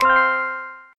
reel_stop_bonus.mp3